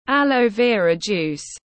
Nước ép nha đam tiếng anh gọi là aloe vera juice, phiên âm tiếng anh đọc là /ˌæl.əʊ ˈvɪə.rə ˌdʒuːs/